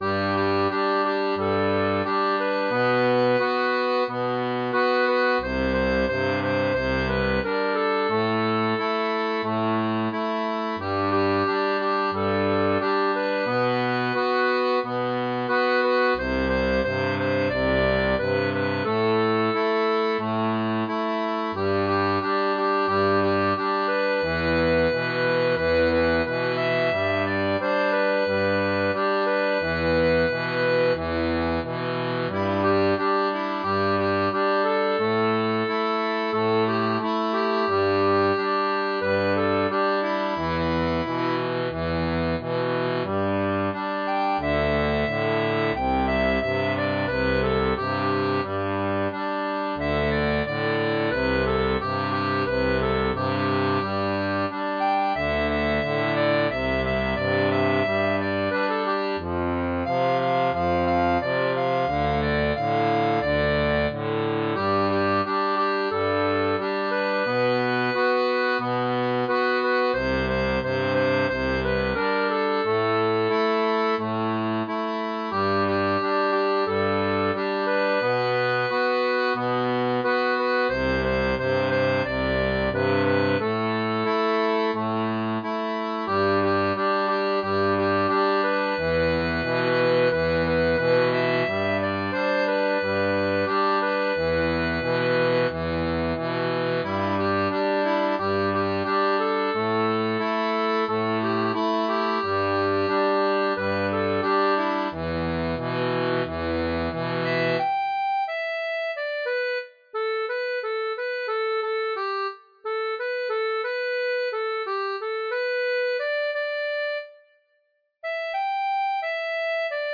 Folk et Traditionnel